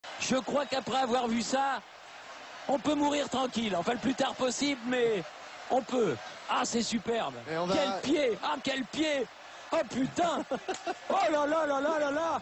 thierry roland mourir tranquille Meme Sound Effect
Category: Sports Soundboard